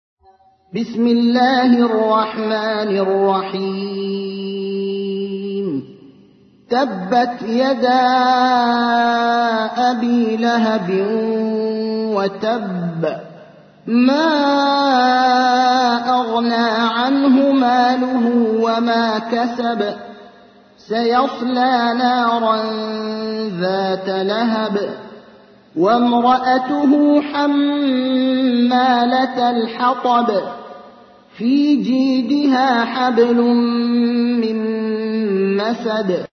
تحميل : 111. سورة المسد / القارئ ابراهيم الأخضر / القرآن الكريم / موقع يا حسين